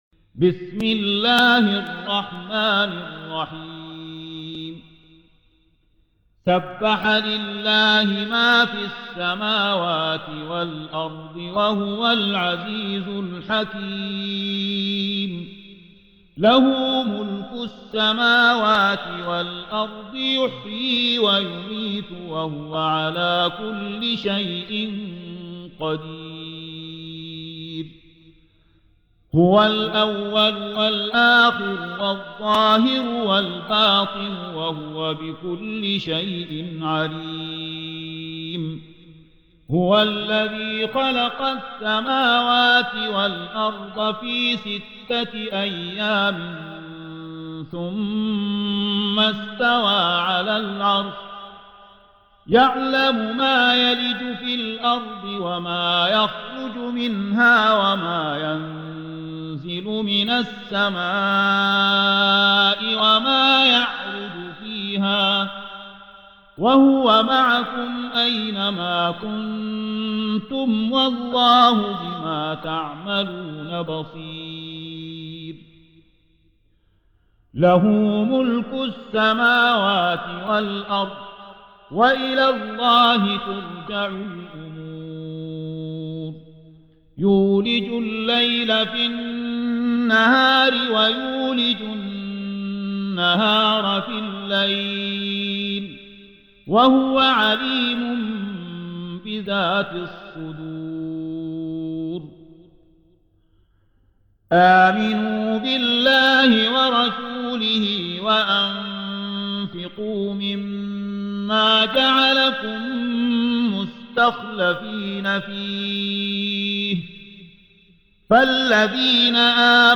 57. Surah Al-Had�d سورة الحديد Audio Quran Tarteel Recitation
Surah Sequence تتابع السورة Download Surah حمّل السورة Reciting Murattalah Audio for 57.